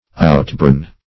\Out"born`\